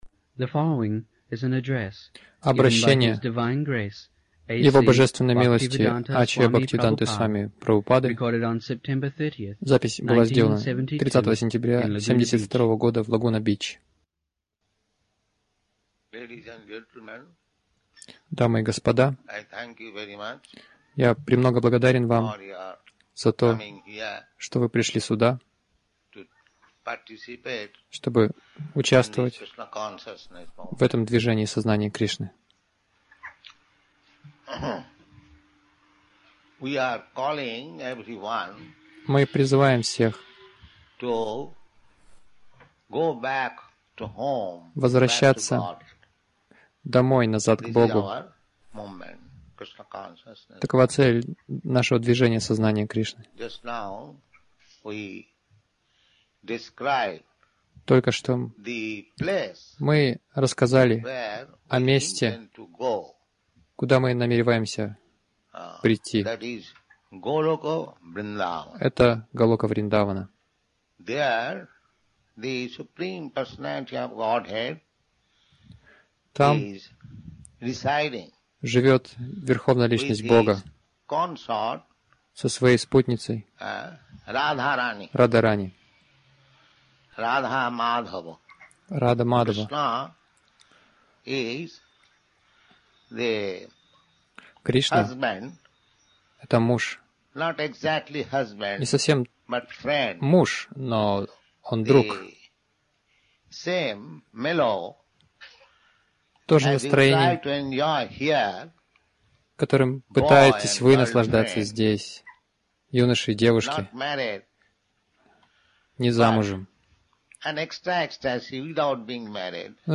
Милость Прабхупады Аудиолекции и книги 30.09.1972 Разное | Лагуна Бич Как подняться над миражом Загрузка...